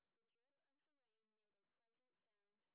sp12_street_snr30.wav